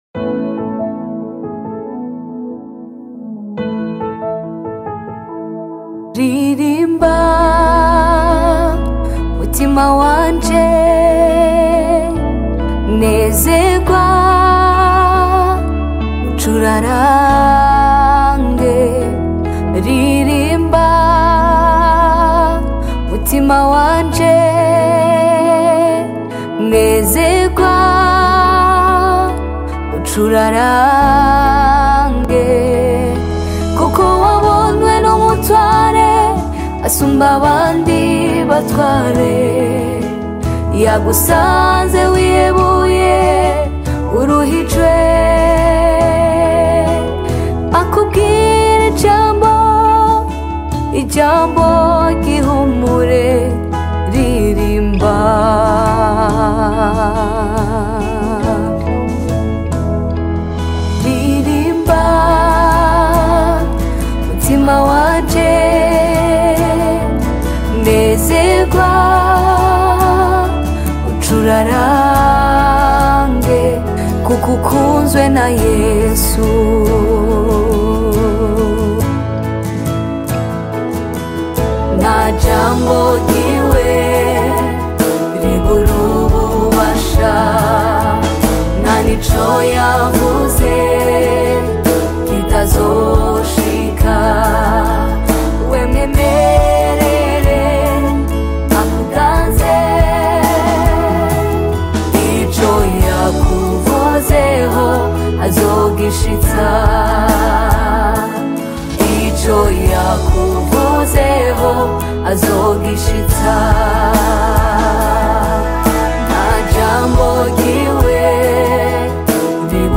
Umuririmvyikazi
Gospel Music